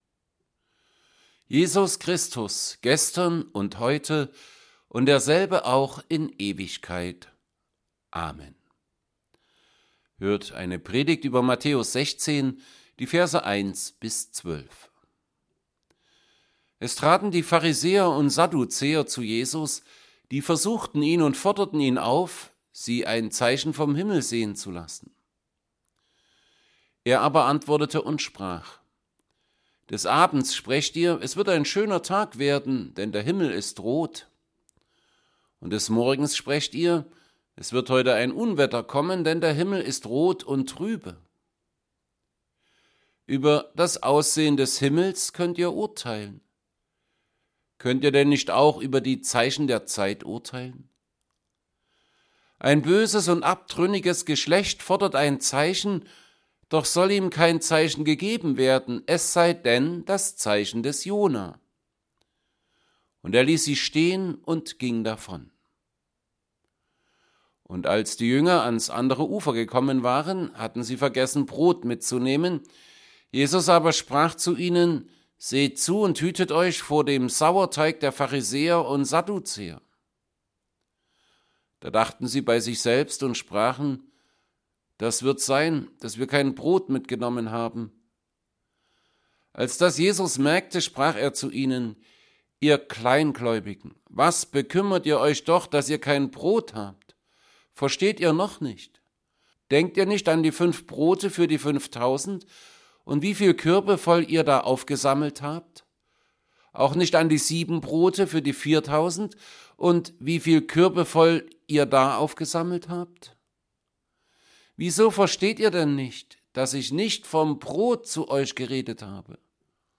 Predigt_zu_Matthaeus_16_1b12A.mp3